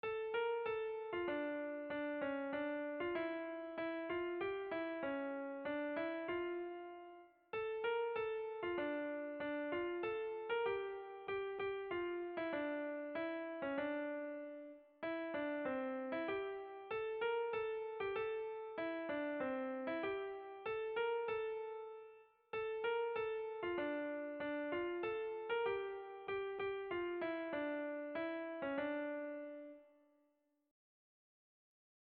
Sentimenduzkoa
Zortziko handia (hg) / Lau puntuko handia (ip)
ABDB